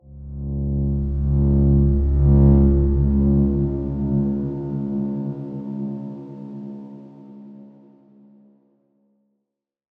X_Darkswarm-C#1-mf.wav